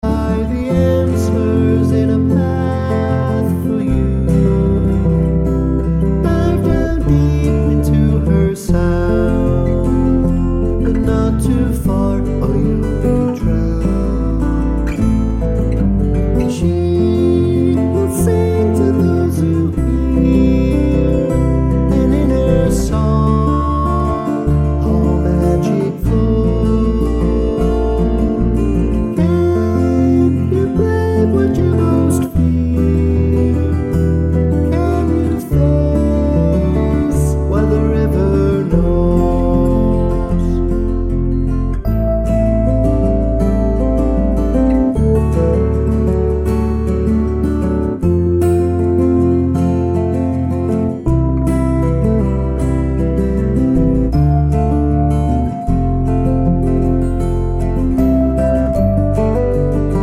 no Backing Vocals Country